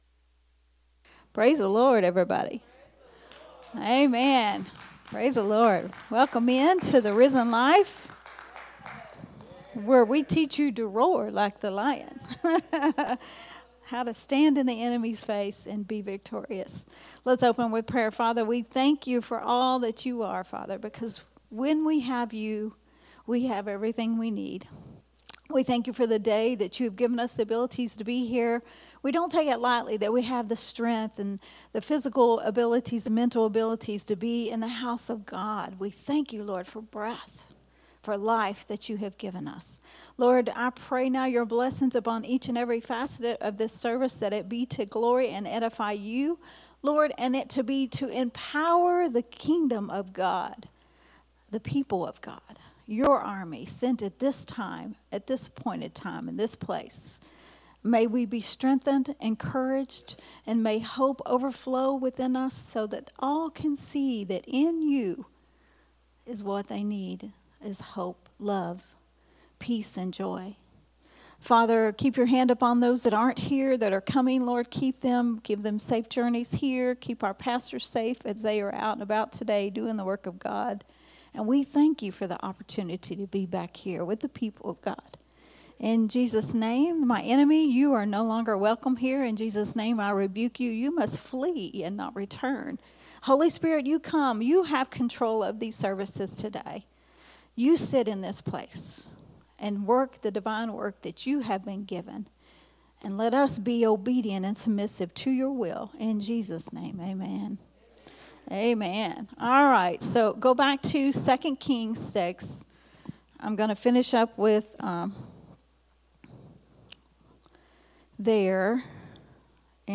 a Sunday Morning Risen Life teaching recorded at Unity Worship Center on 9/1/2024.